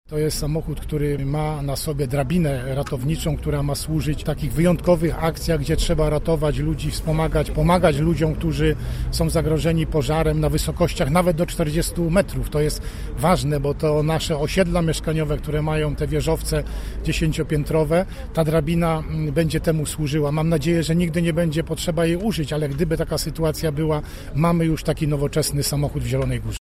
Oficjalne przekazanie pojazdu odbyło się dzisiaj. Sprzęt o wartości 2,5 mln zł podarował funkcjonariuszom PSP Władysław Dajczak, wojewoda lubuski: